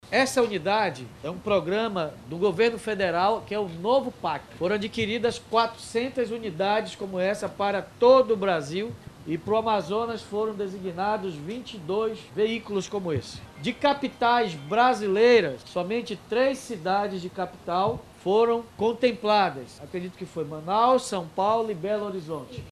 A estrutura permite até 20 atendimentos diários e conta com cadeira odontológica, raio-x, autoclave, gerador, ar-condicionado e outros equipamentos essenciais, explica o Prefeito, David Almeida.